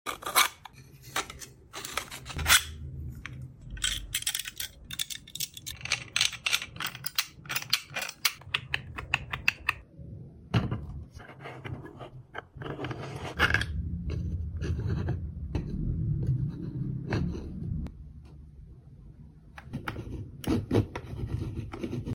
3D Printed Herringbone Gear Phone